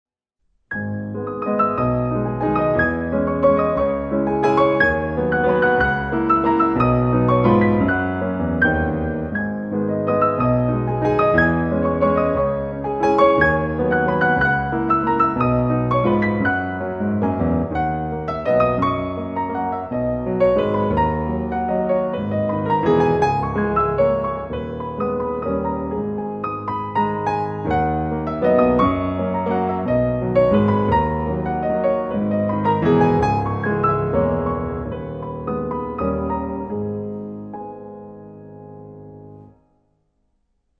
duo de pianos